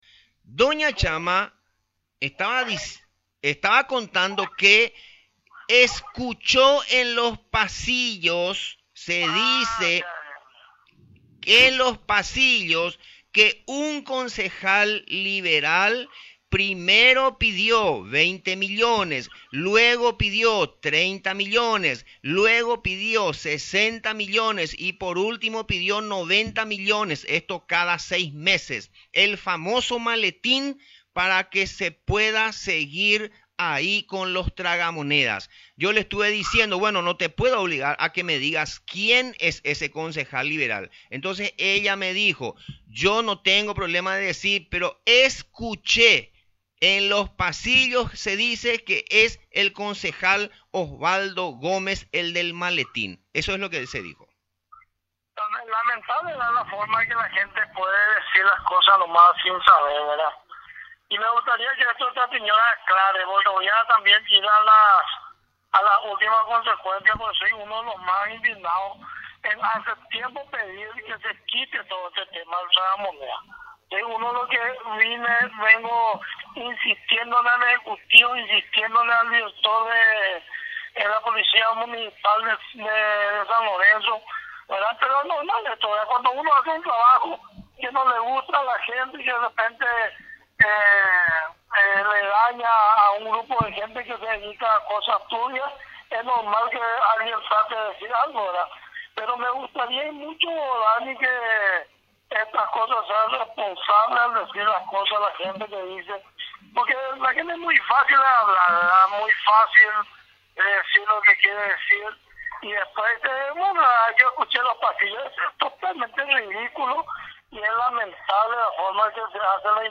Parte de la entrevista al concejal Gómez